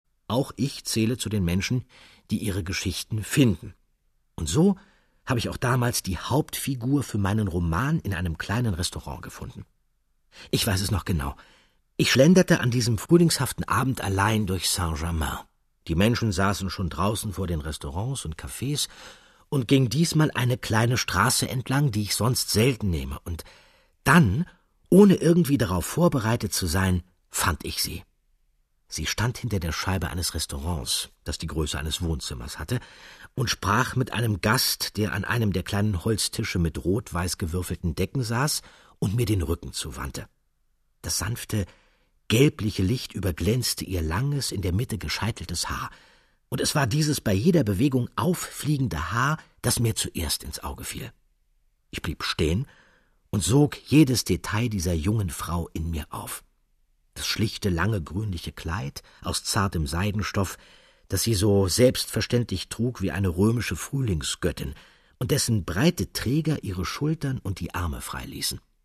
Andreas Fröhlich, Stefanie Stappenbeck (Sprecher)
2012 | 4. Auflage, Gekürzte Ausgabe